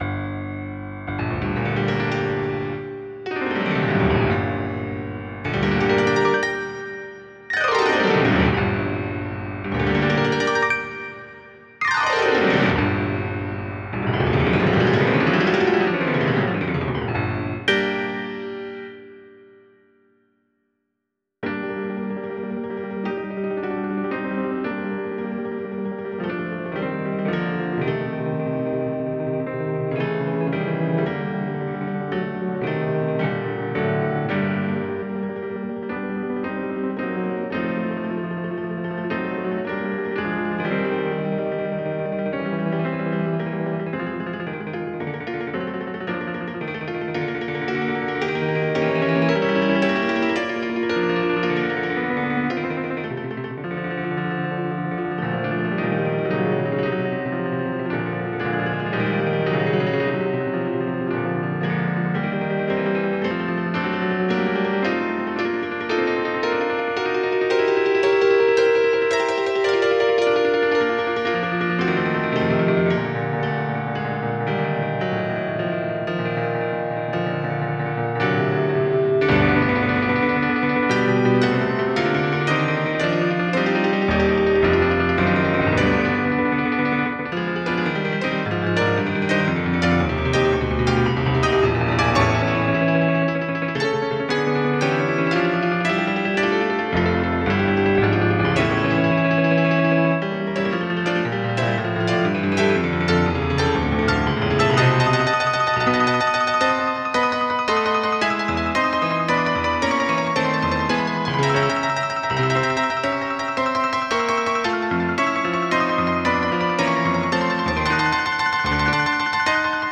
variación
música clásica